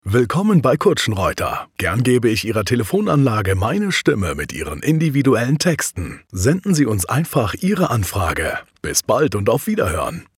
Im Studio werden Ihre Ansagen individuell für Sie produziert – KI-generierte Ansagen oder Texte ‚von der Stange‘ gibt’s bei uns nicht.